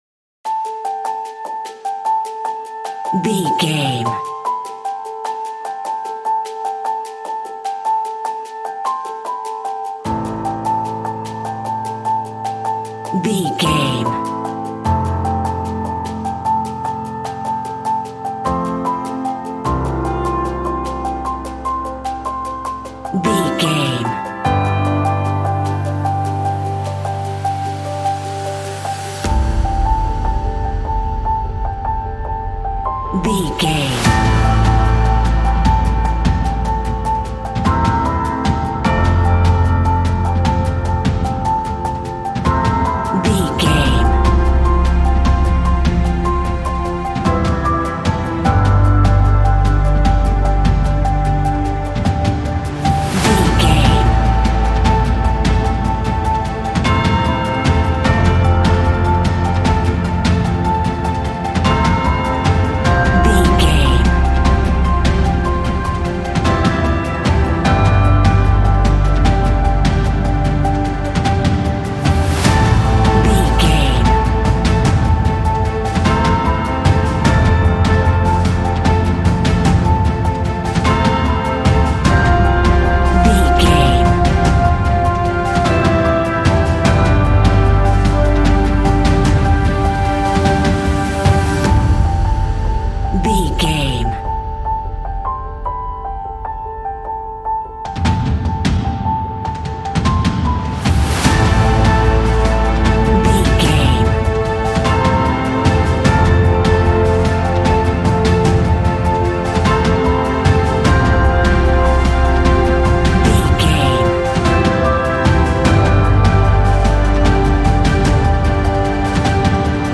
Epic / Action
In-crescendo
Ionian/Major
Slow